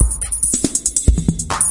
70 bpm Drum Loops " Boss70
描述：用氢气制作的70bpm鼓循环
Tag: 节拍 电子